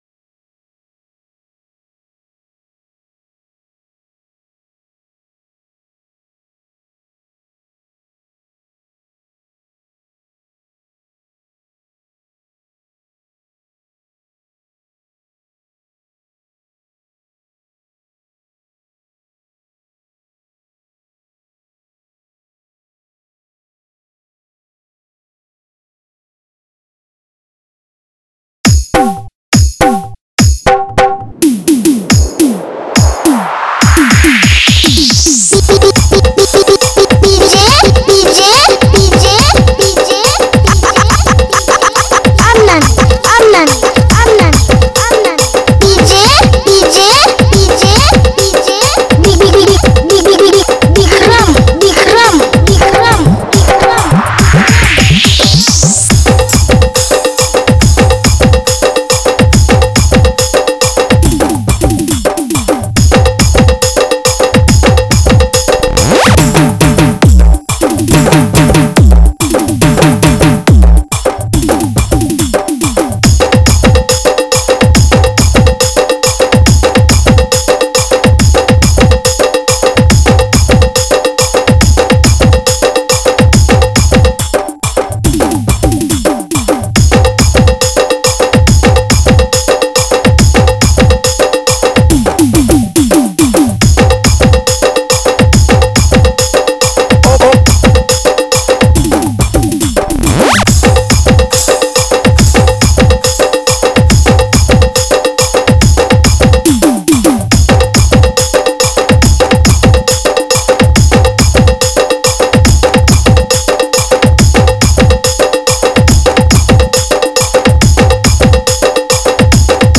high-energy Nagpuri track